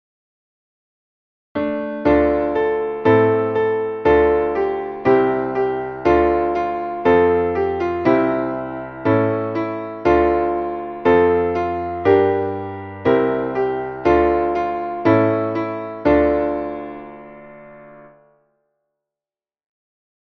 Traditionelles Volks-/ Winter-/ Weihnachtslied